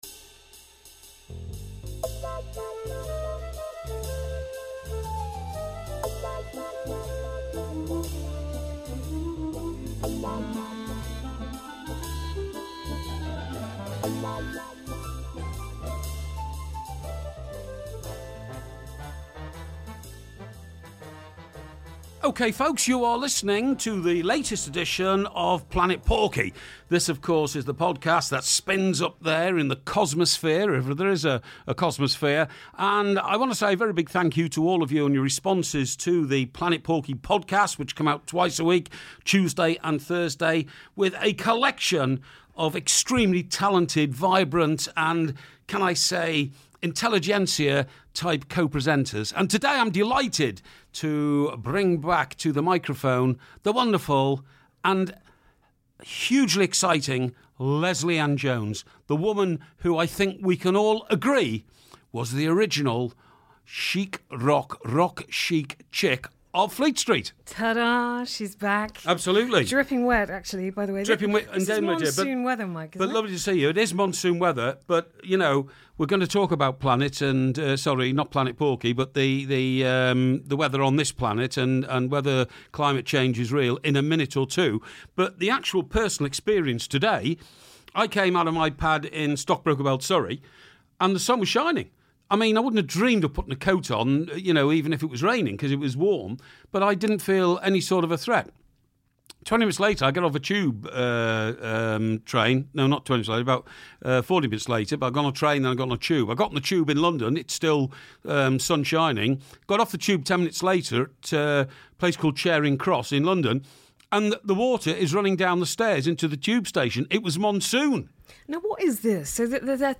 LAJ is back with Mike Parry for more eyelash fluttering, flirtatious banter and cutting commentary (and that's just the Porkmeister). Today's topics include Greta Thunberg, Mick Jagger, the appeal of Ed Sheeran, Woodstock and the reclusive tale of John Deacon.